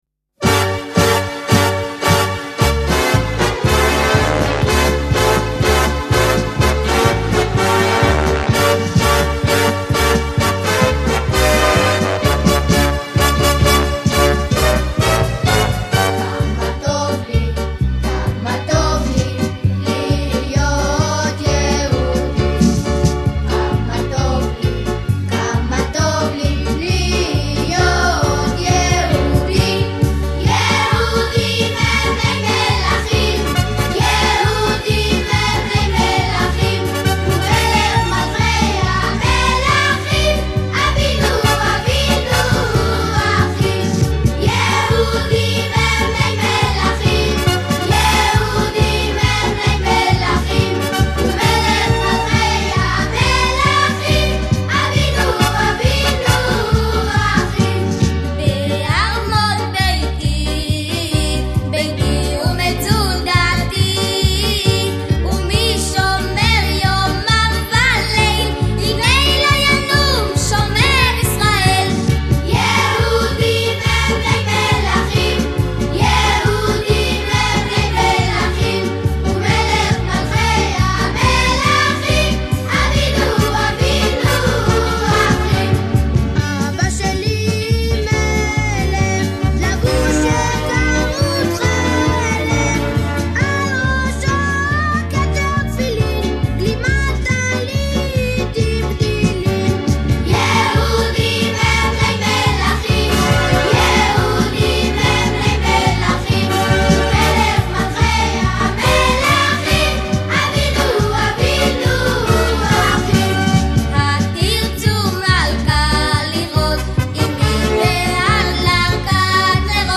שיר ילדים עברי מקסים שמיועד לכיתה החל מ א'.